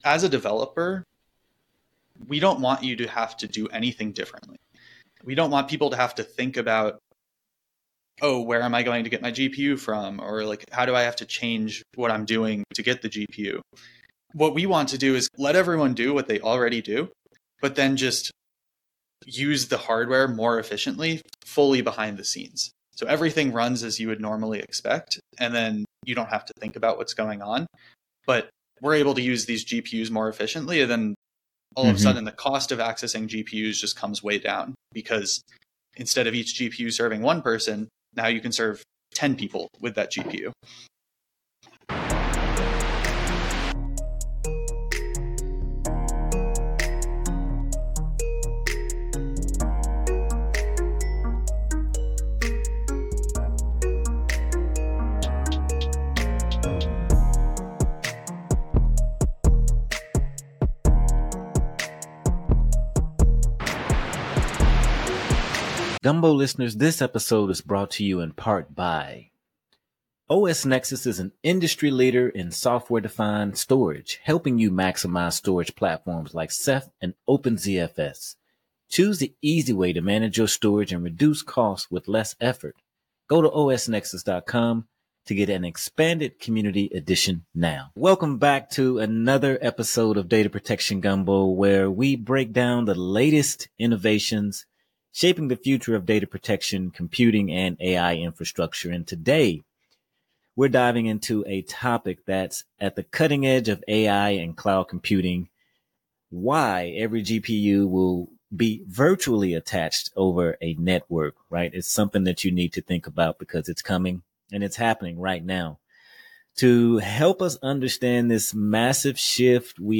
This engaging conversation sheds light on cutting-edge tools like Vectra AI and the human side of battling cyber threats.